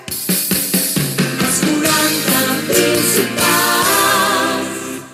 Indicatiu del programa en català
FM